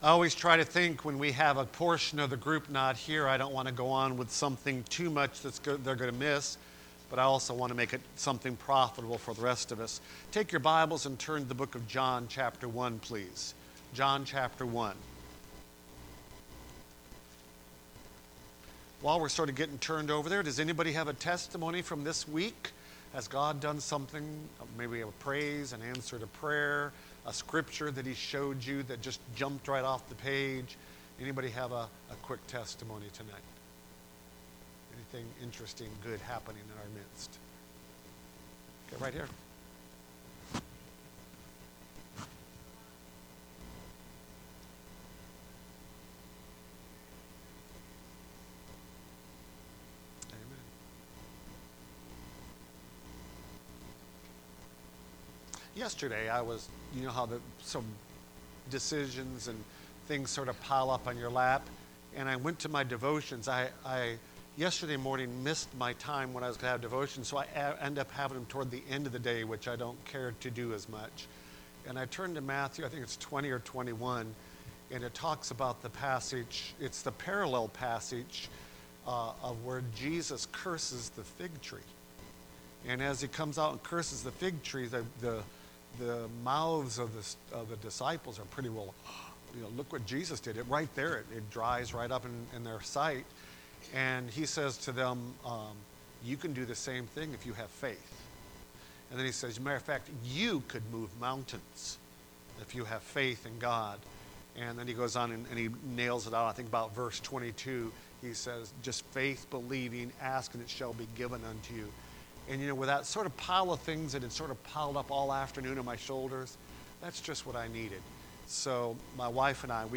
Service Type: Wednesday Prayer Service